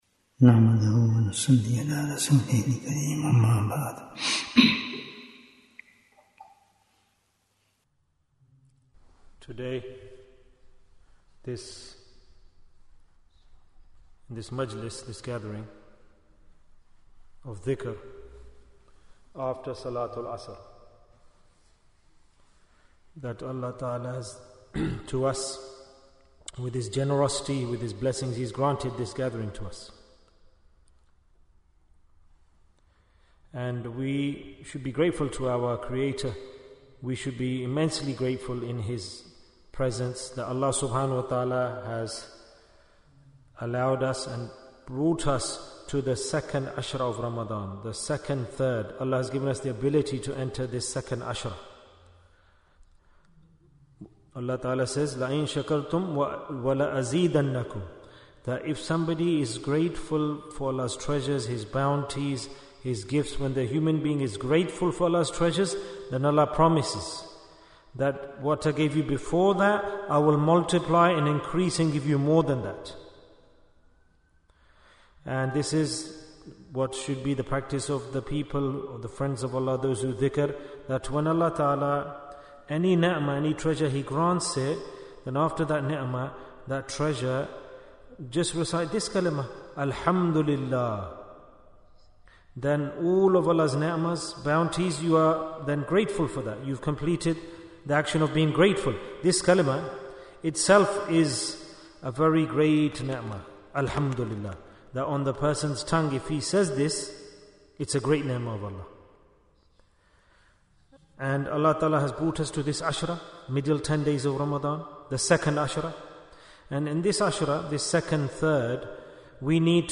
Jewels of Ramadhan 2025 - Episode 14 - Which Deed Should We Do in the Second Asharah? Bayan, 52 minutes11th March, 2025